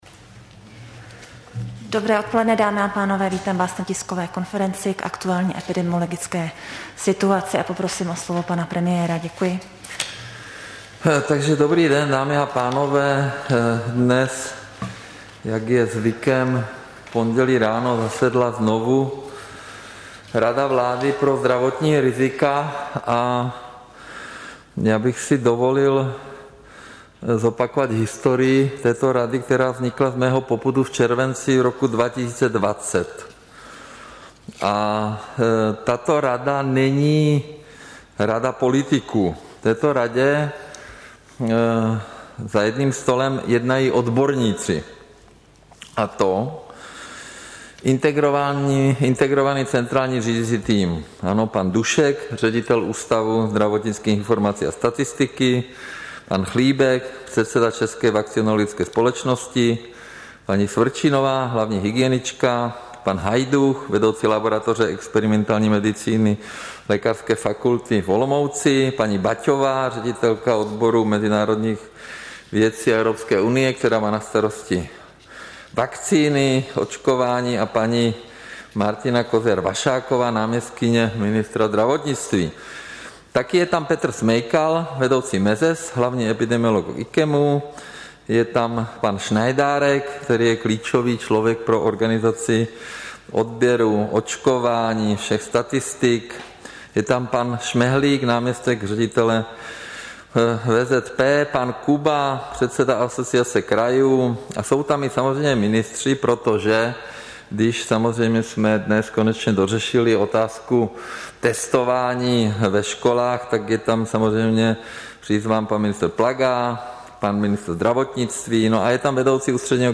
Tisková konference k aktuální epidemiologické situaci, 25. října 2021